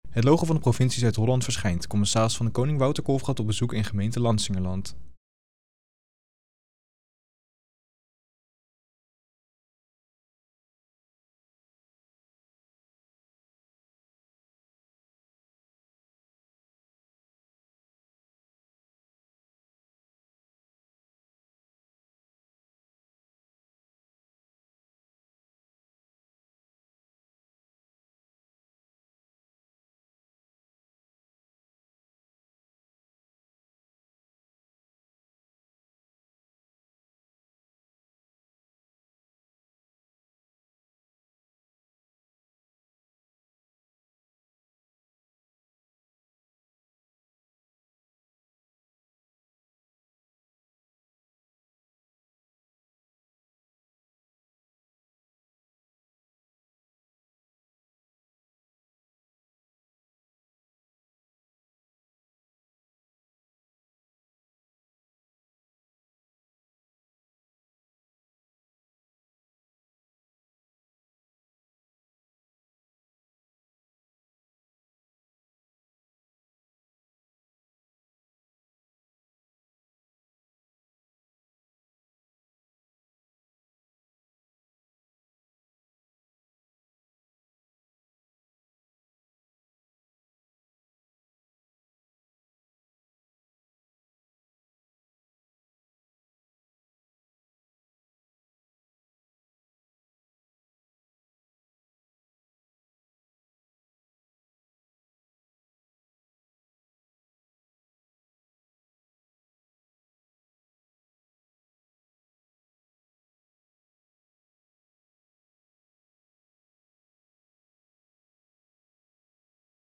In gesprek met burgemeester Lansingerland
De commissaris van de Koning bezoekt de komende tijd alle 50 gemeenten van Zuid-Holland. In deze video gaat hij in gesprek met de burgemeester van Lansingerland.